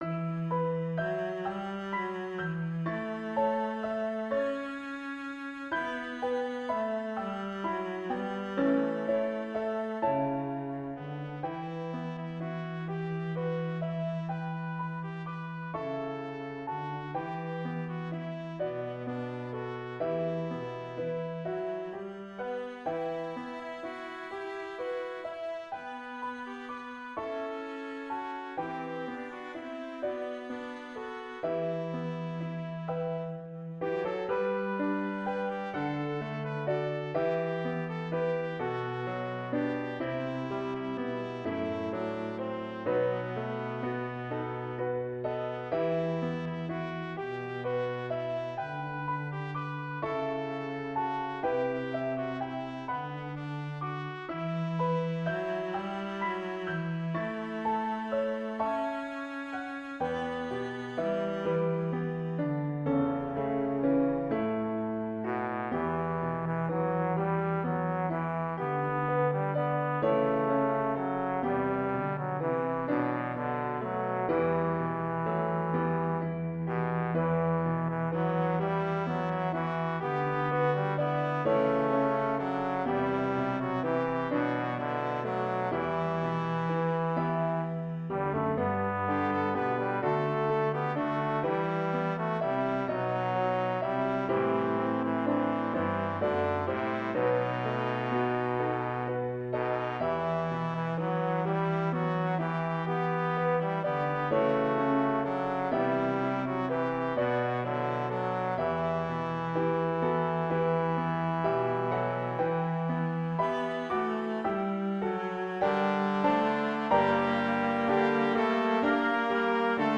Voicing/Instrumentation: 2 part choir , Duet
Cello Optional Obbligato/Cello Accompaniment